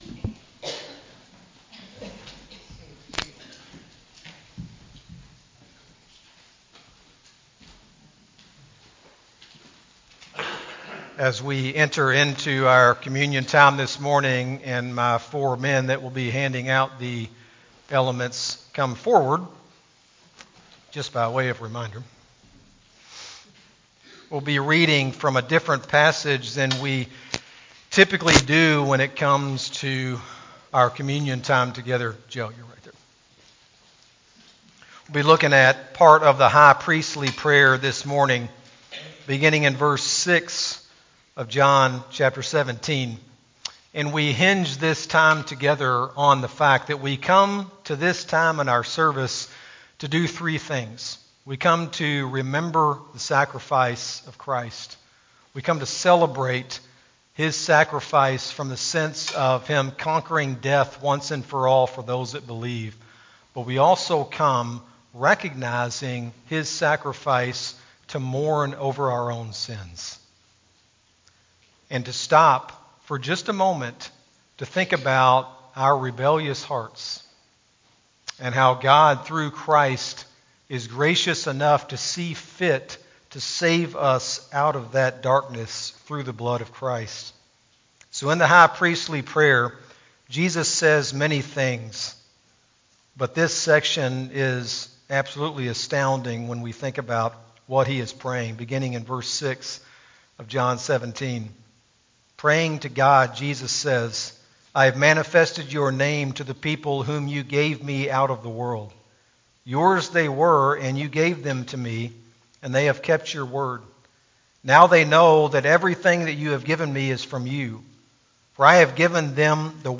Sermon-12-3-23-CD.mp3